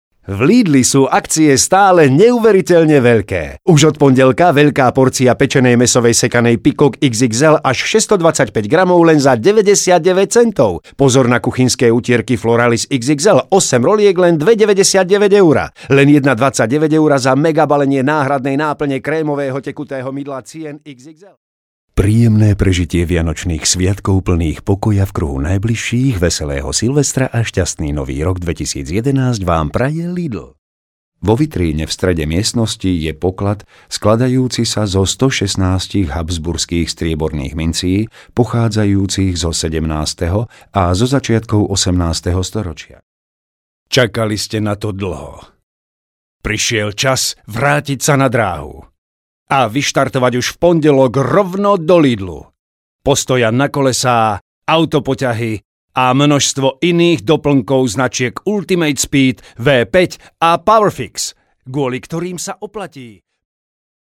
Professionelle Sprecher und Sprecherinnen
Männlich